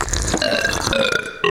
• MULTIPLE ECHOED BELCHES.wav
MULTIPLE_ECHOED_BELCHES_60e.wav